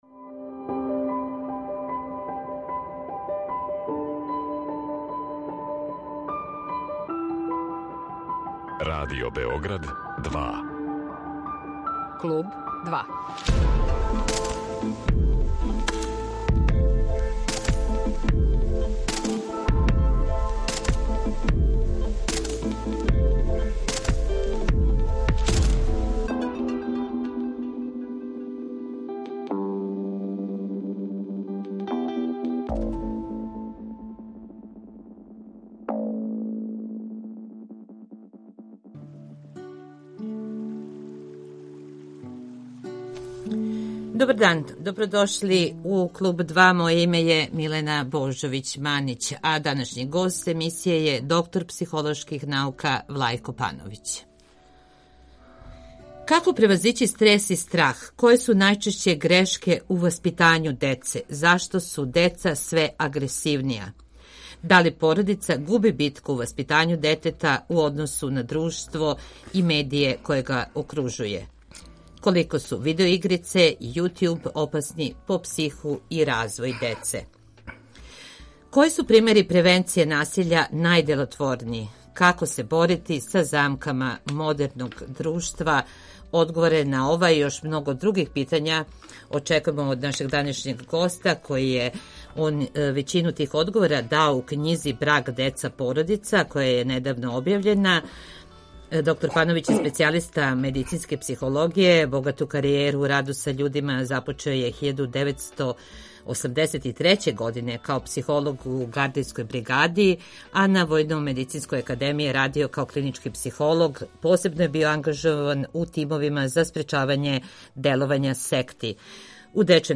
Гост емисије је доктор психолошких наука